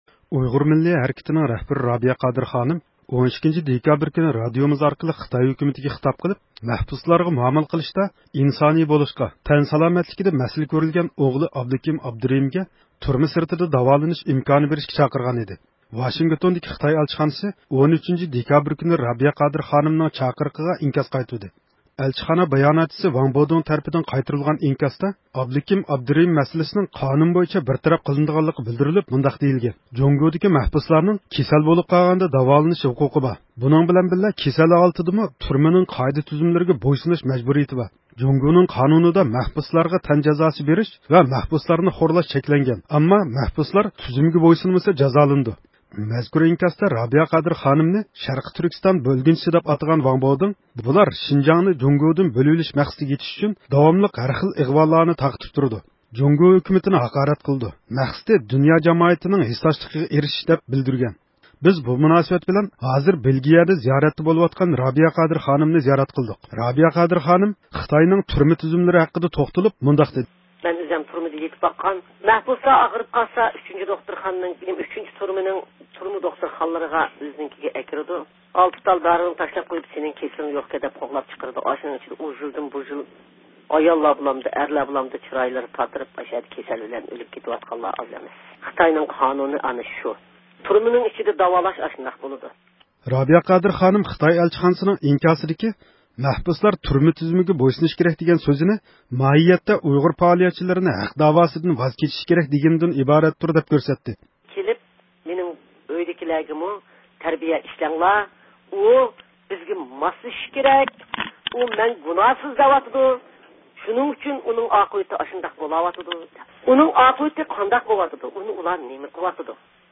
بۇ مۇناسىۋەت بىلەن، ھازىر بېلگىيىدە زىيارەتتە بولۇۋاتقان رابىيە قادىر خانىمنى زىيارەت قىلدۇق.